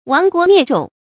亡国灭种 wáng guó miè zhǒng
亡国灭种发音
成语注音 ㄨㄤˊ ㄍㄨㄛˊ ㄇㄧㄝ ˋ ㄓㄨㄙˇ